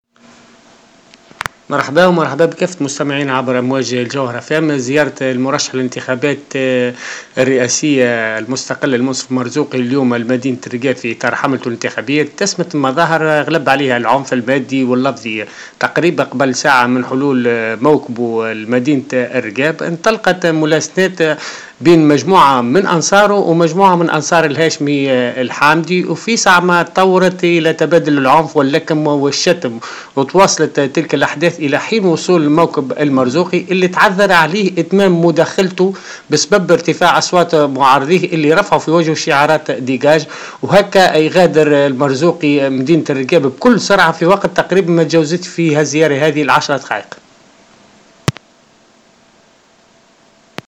Notre correspondant t√©l√©charger partager sur